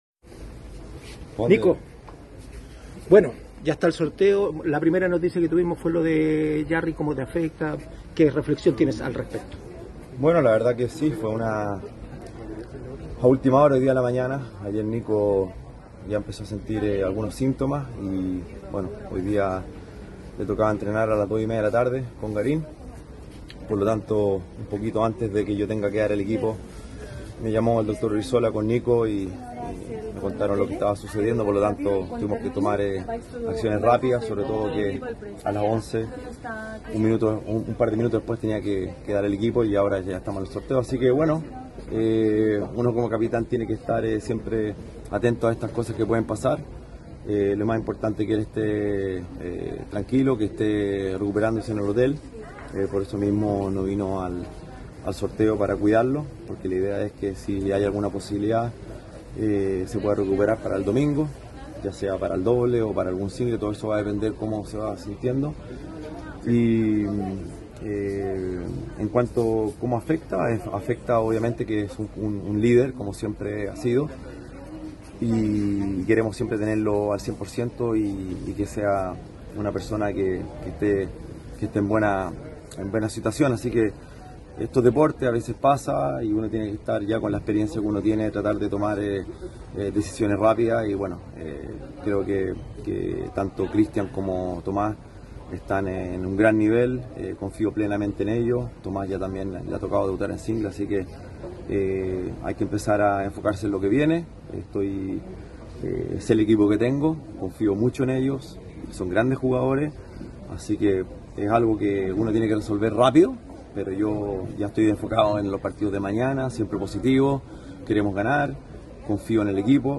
En diálogo con ADN Deportes desde Hasselt, el capitán del equipo chileno abordó la ausencia de su principal raqueta en el inicio de la serie contra Bélgica.